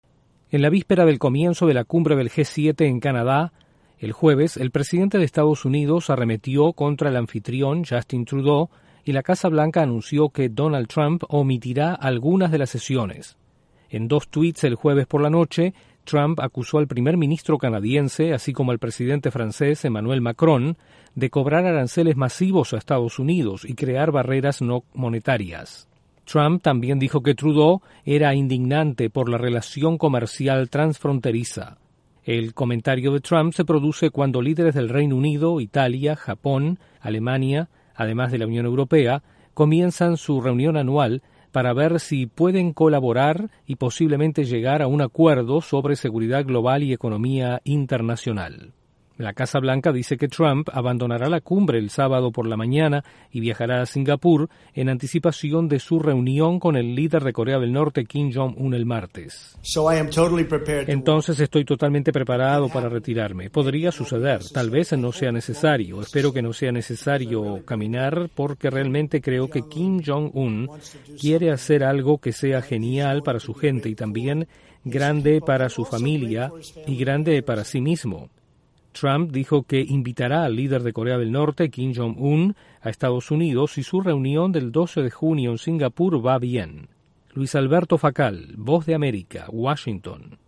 La Casa Blanca dice que el presidente Donald Trump omitirá algunas de las sesiones de la cumbre del G-7 en Canadá. Desde la Voz de América en Washington informa